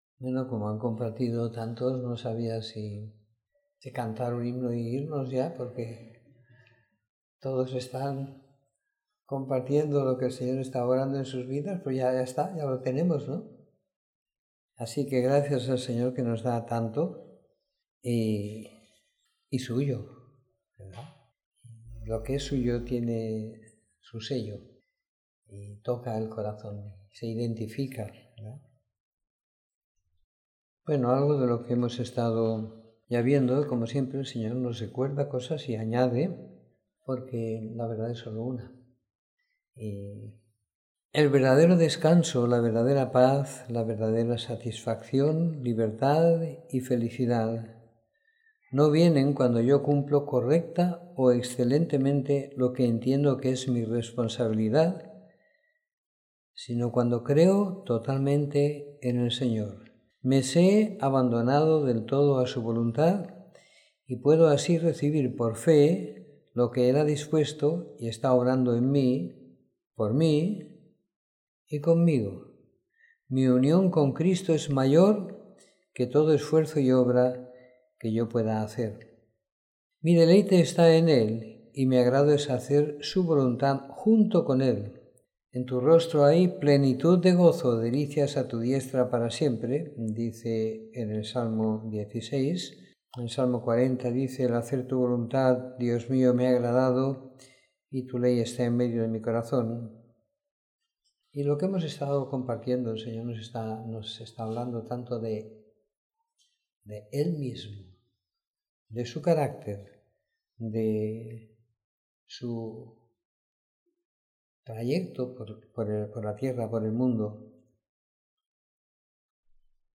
Reunión semanal de compartir la Palabra y la Vida.